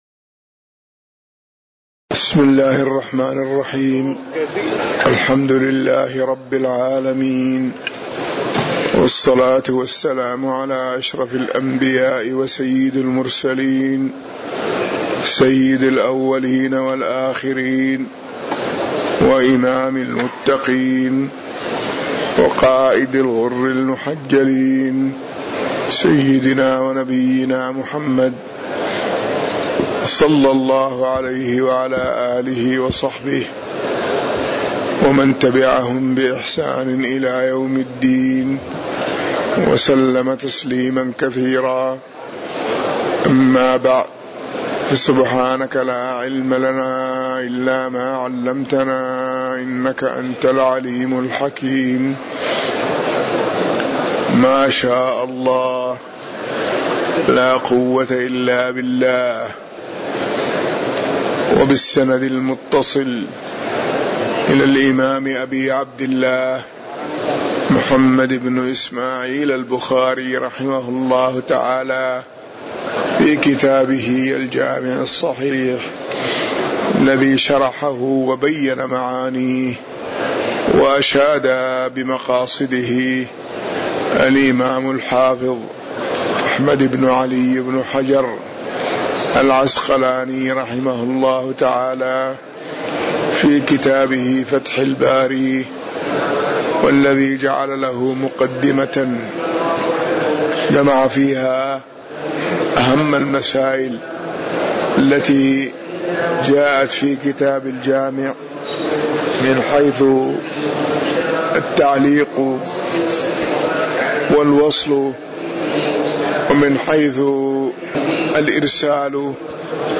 تاريخ النشر ١١ ذو القعدة ١٤٤٠ هـ المكان: المسجد النبوي الشيخ